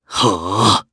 Clause-Vox_Casting3_jp.wav